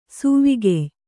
♪ suvvigey